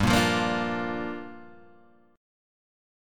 Gm7 chord